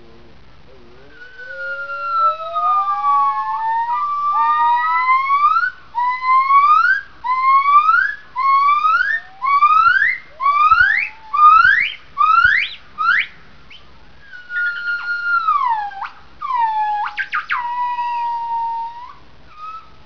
Females produce great calls only.
Male produces multi-modulated phrase as coda. Duet song bouts.
b. great call sequnce consisting of female great call with male contribution (Mulhouse Zoo, France, 13 Sept. 1988)
Press to start sound Duet song, adult pair "Charlot" and "Emily", Zoo Mulhouse, France, 13 Sept. 1988.
The infant offspring (1 year 3 months old) of this pair contributes only few whistles to this song bout.